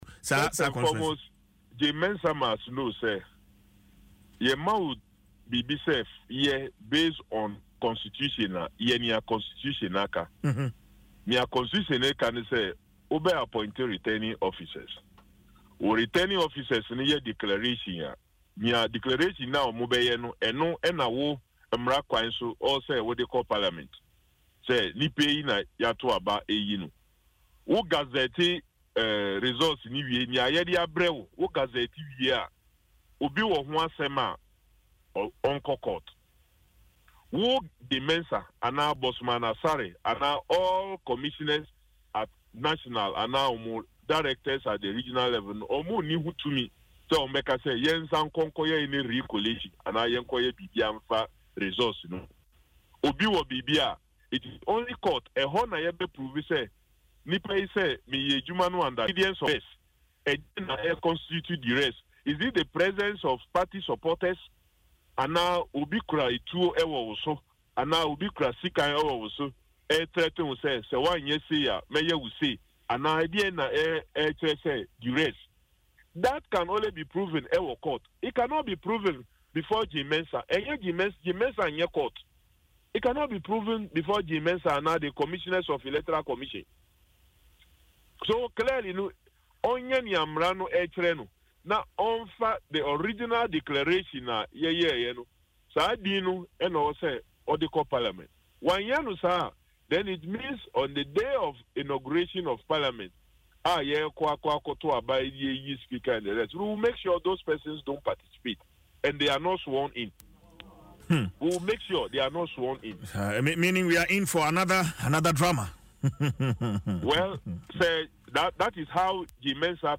He disclosed this in an interview on Adom FM’s morning show, Dwaso Nsem, stating that, the law does not mandate the Electoral Commission(EC) to overturn declarations which were made at original collation centres.